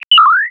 open-safe.16.wav